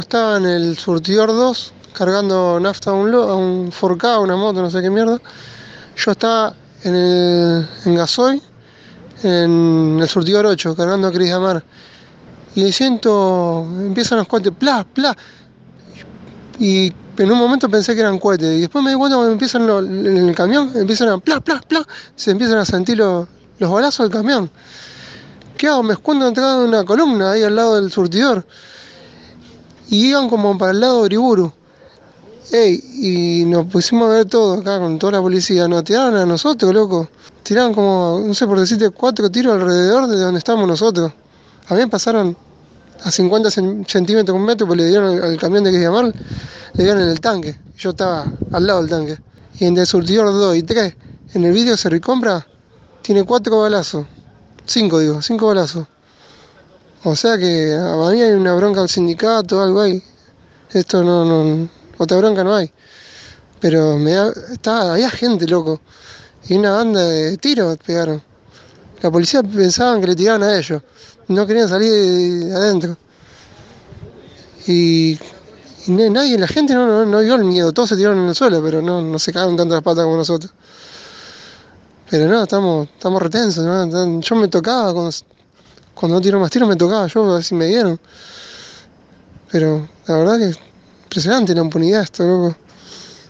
Un playero de la estación de servicio dio su testimonio sobre lo ocurrido y manifestó el temor que tuvieron tras escuchar las balas.